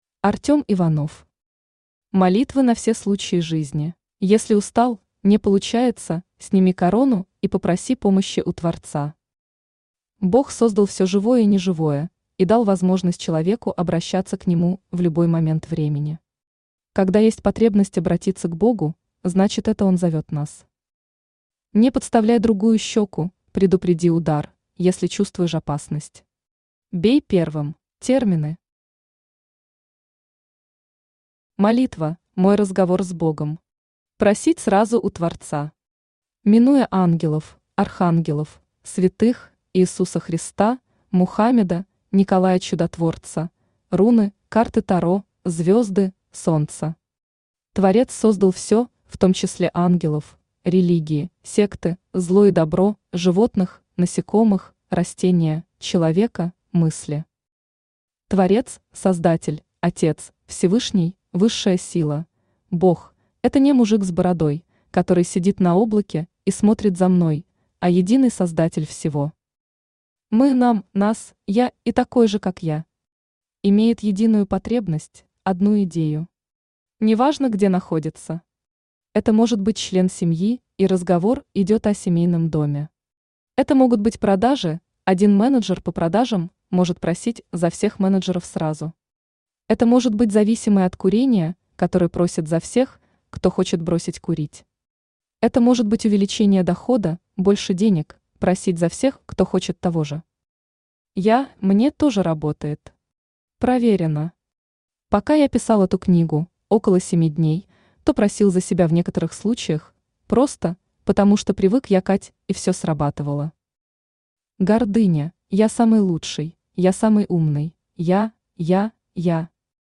Аудиокнига Молитвы на все случаи жизни | Библиотека аудиокниг
Aудиокнига Молитвы на все случаи жизни Автор Артём Игоревич Иванов Читает аудиокнигу Авточтец ЛитРес.